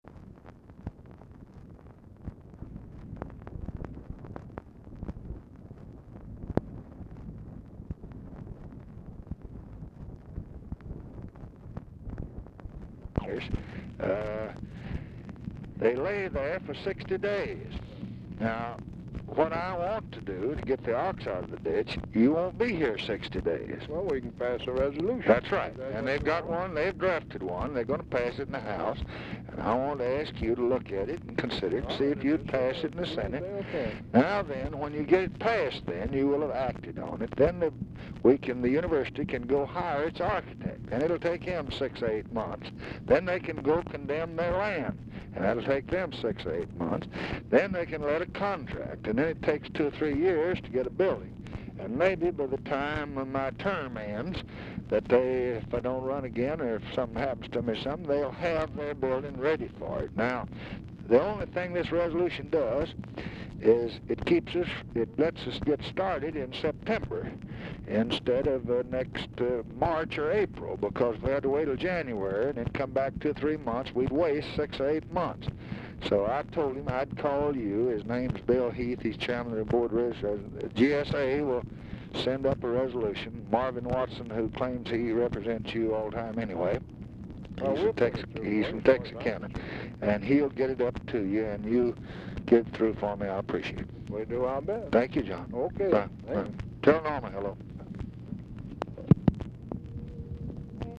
Telephone conversation # 8554, sound recording, LBJ and JOHN MCCLELLAN, 8/18/1965, 2:00PM | Discover LBJ
Format Dictation belt
Location Of Speaker 1 Oval Office or unknown location
Specific Item Type Telephone conversation